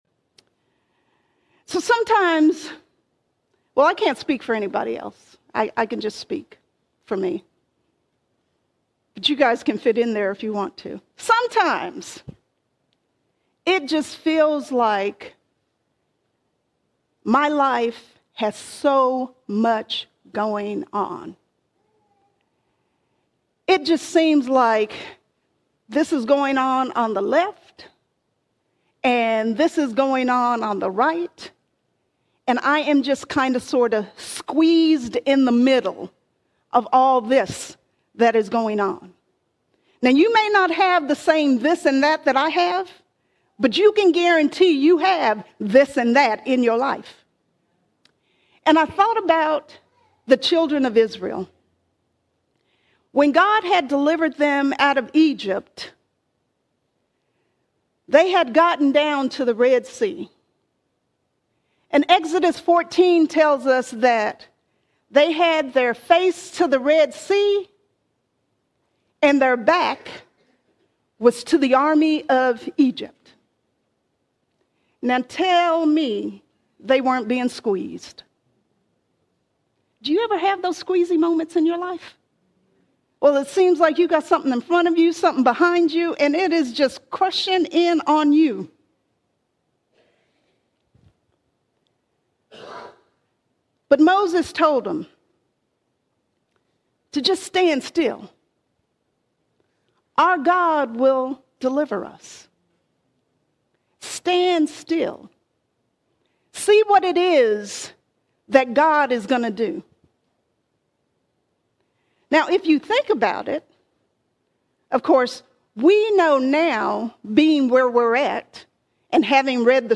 13 October 2025 Series: Sunday Sermons All Sermons Empowered To Overcome Empowered To Overcome In Christ Jesus, In all things we have been empowered to overcome.